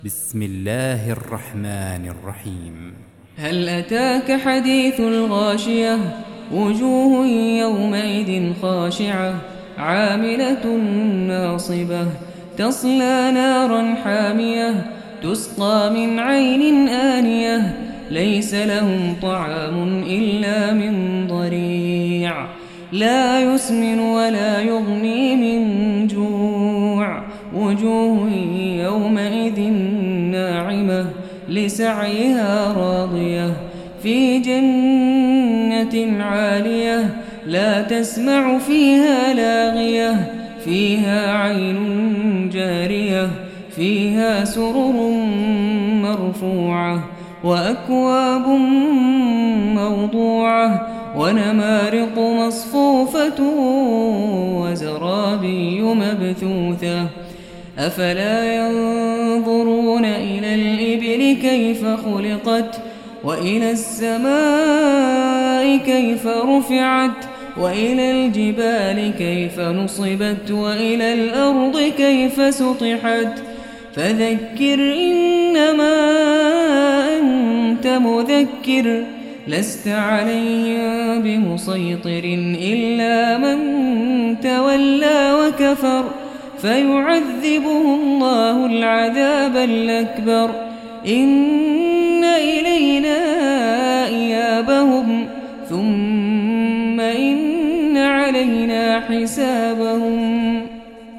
Audio Quran Tarteel Recitation
Surah Repeating تكرار السورة Download Surah حمّل السورة Reciting Murattalah Audio for 88. Surah Al-Gh�shiyah سورة الغاشية N.B *Surah Includes Al-Basmalah Reciters Sequents تتابع التلاوات Reciters Repeats تكرار التلاوات